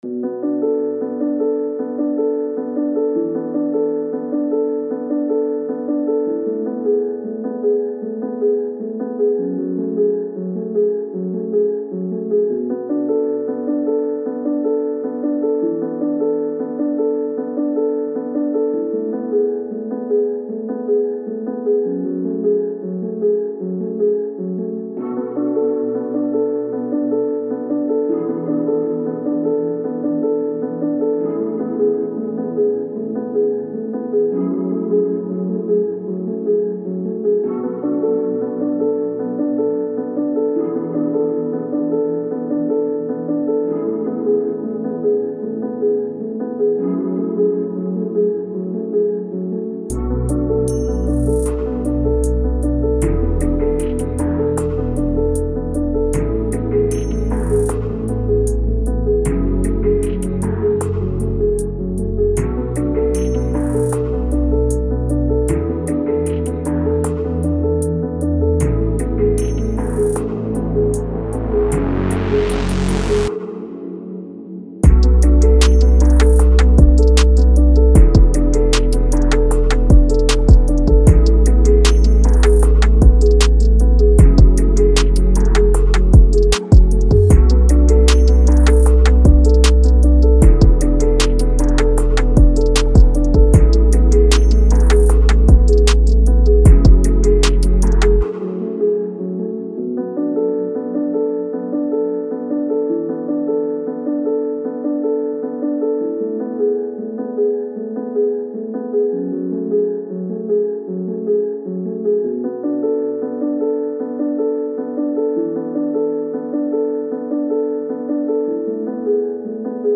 Dark
R&B, Soul, Hip Hop